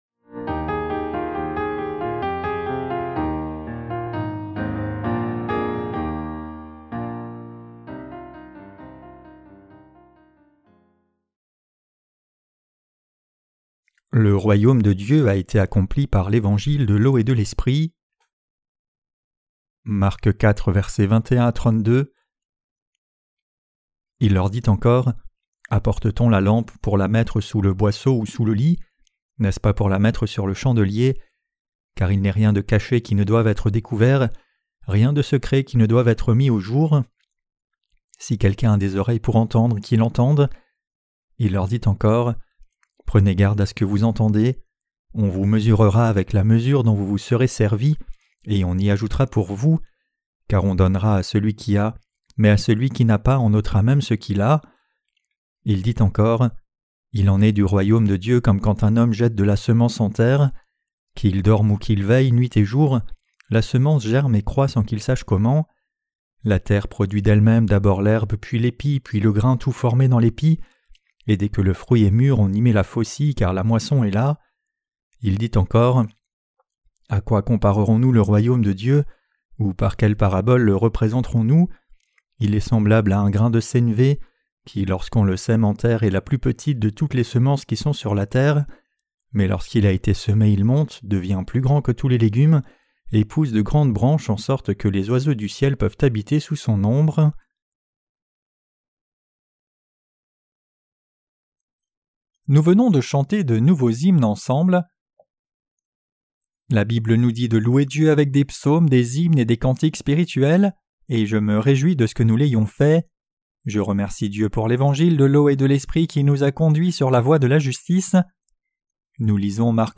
Sermons sur l’Evangile de Marc (Ⅰ) - QUE DEVRIONS-NOUS NOUS EFFORCER DE CROIRE ET PRÊCHER? 10.